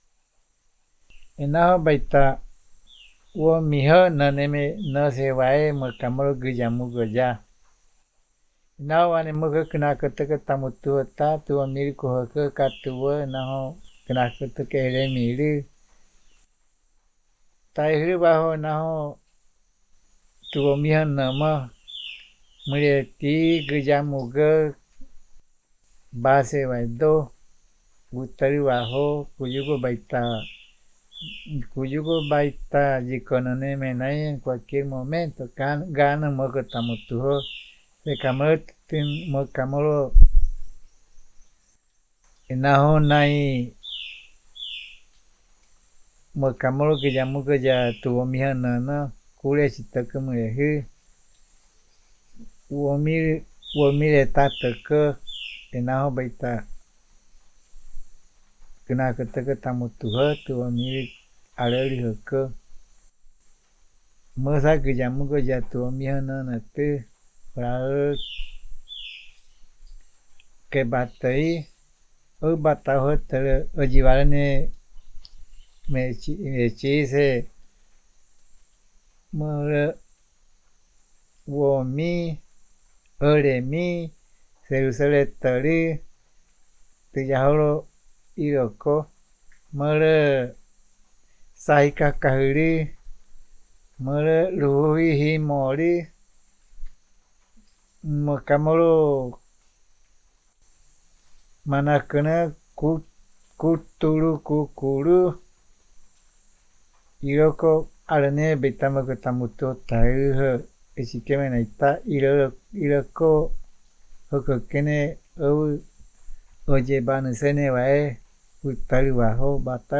Puerto Nare, Guaviare